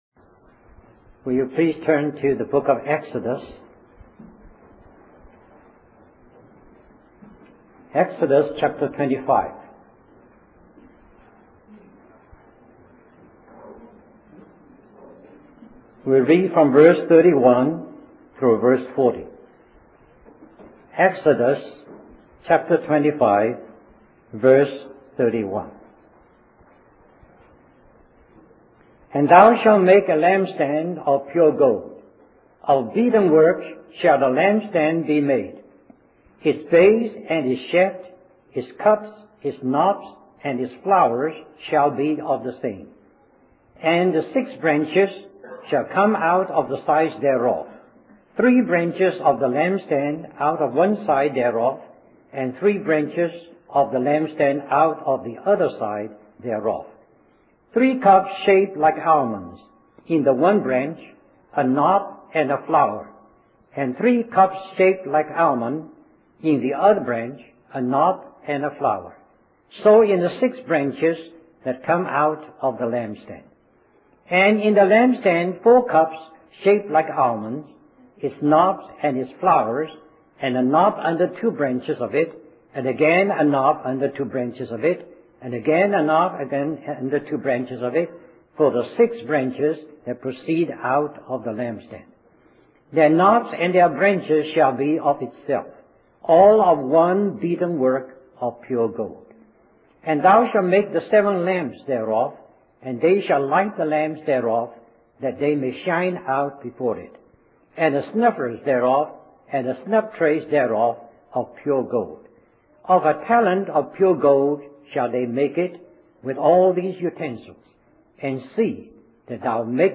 Conference at Bible Institute of Los Angeles